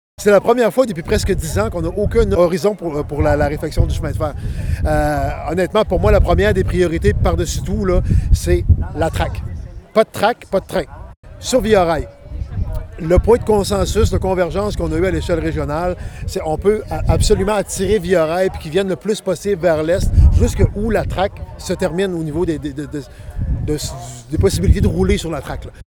Tout comme Alexis Deschênes, Daniel Côté souhaite d’abord et avant tout que le rail se rende simplement jusqu’à la fin du tracé prévu. On écoute le maire de Gaspé :